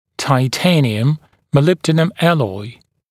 [tɪ’teɪnɪəm mə’lɪbdənəm ‘ælɔɪ] [‘biːtə taɪ’teɪnɪəm][ти’тэйниэм мэ’либдэнэм ‘элой] [‘би:тэ тай’тэйниэм]вольфрам-молибденовый сплав, бета-титан